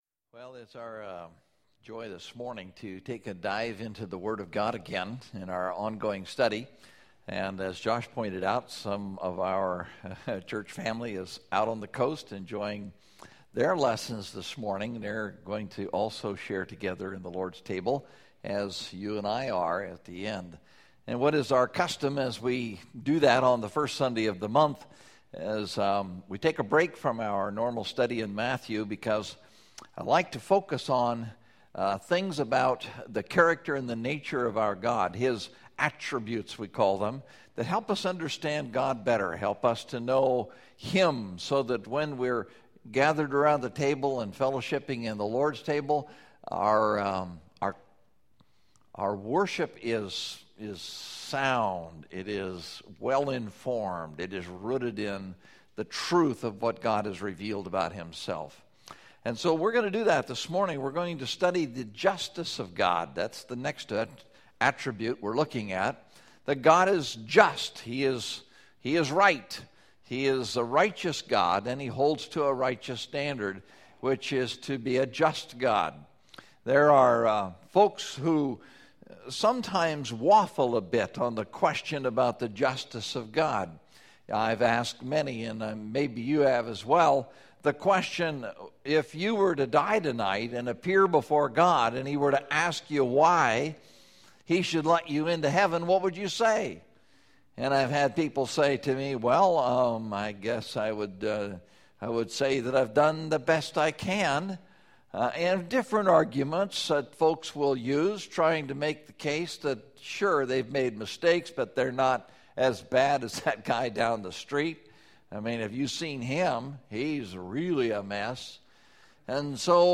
God Is Just (Exodus 34:5-7) – Mountain View Baptist Church